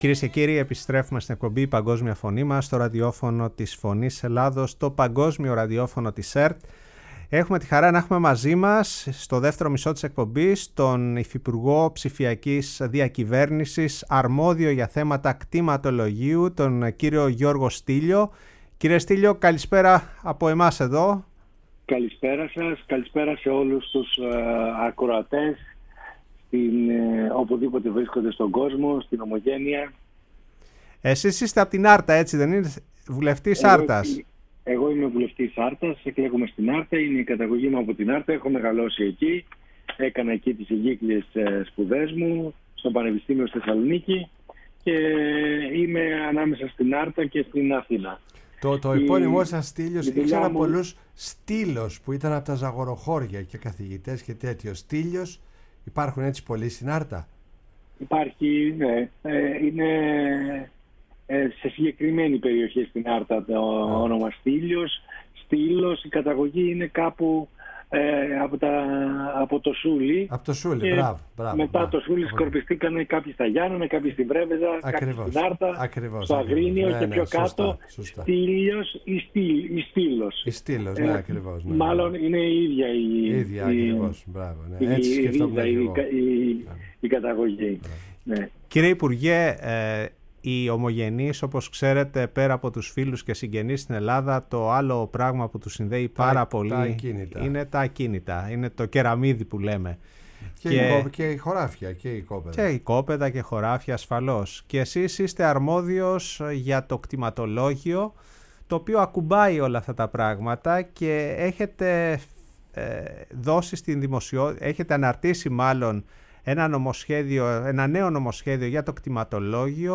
Για τις αλλαγές που φέρνει το νέο νομοσχέδιο για το κτηματολόγιο μίλησε στην εκπομπή «Η Παγκόσμια Φωνή μας» στο Ραδιόφωνο της Φωνής της Ελλάδας ο κ. Γιώργος Στύλιος, υφυπουργός Ψηφιακής Διακυβέρνησης αρμόδιος για θέματα κτηματολογίου.